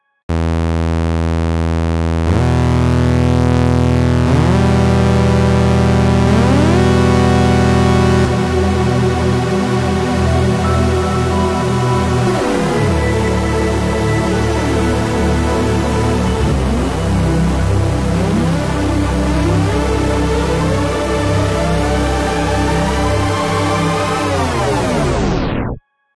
square-alarm.wav